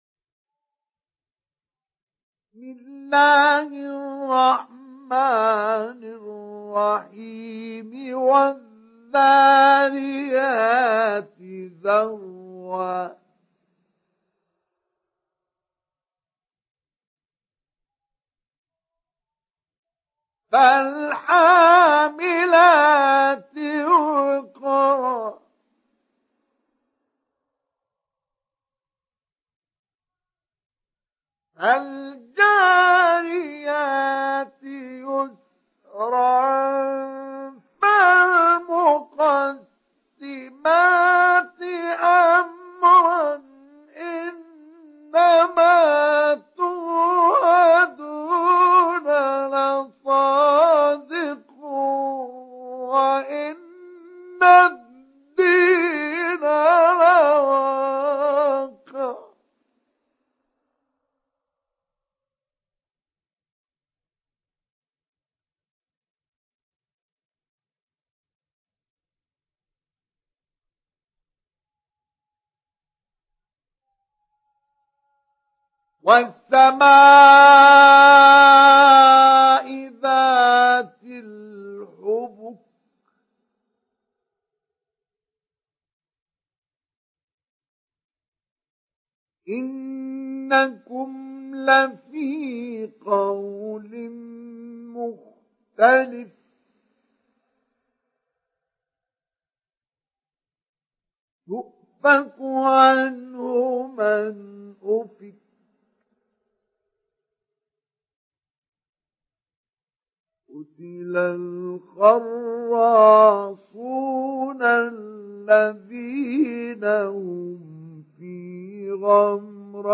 Surat Ad Dariyat Download mp3 Mustafa Ismail Mujawwad Riwayat Hafs dari Asim, Download Quran dan mendengarkan mp3 tautan langsung penuh
Download Surat Ad Dariyat Mustafa Ismail Mujawwad